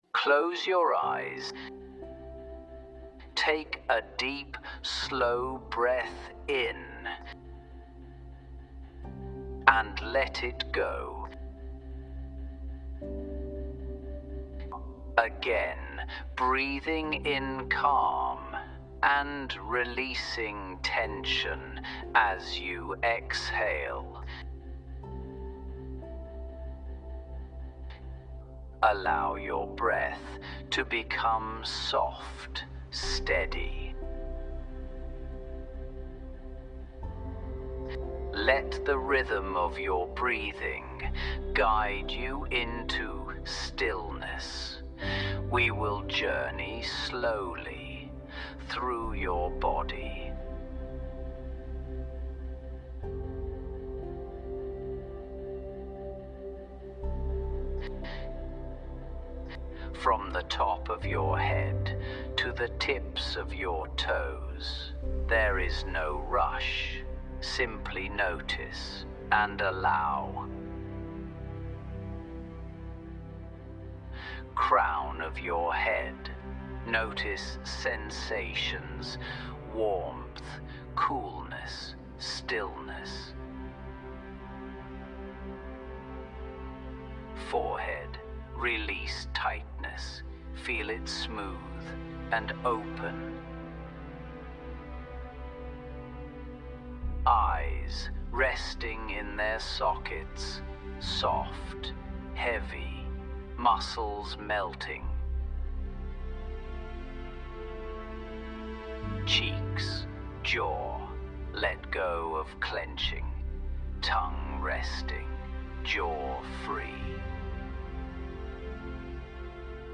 This practice takes you on a slow journey of awareness through your body, beginning at the crown of your head and ending at your toes.